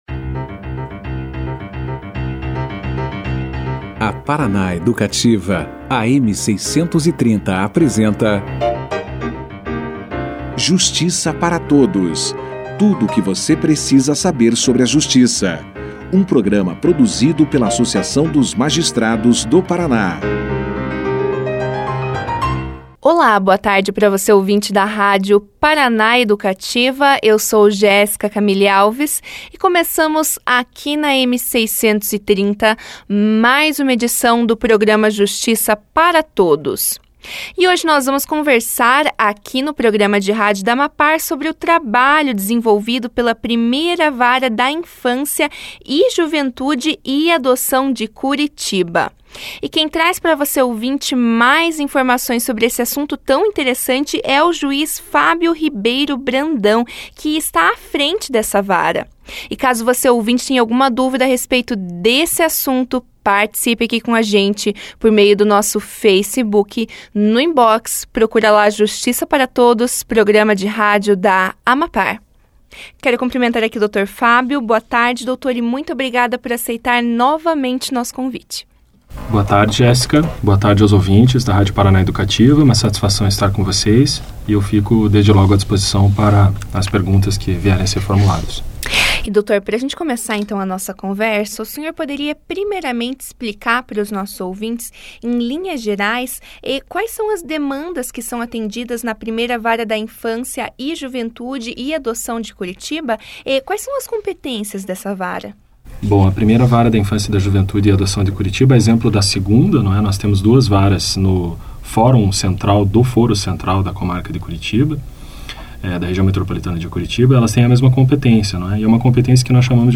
O juiz Fábio Ribeiro Brandão participou na sexta-feira (22) do programa de rádio da AMAPAR, o Justiça para Todos. Ele trouxe aos ouvintes da Paraná Educativa mais informações sobre o trabalho desenvolvido na 1ª Vara da Infância e Juventude e Adoção de Curitiba, na qual o magistrado atua.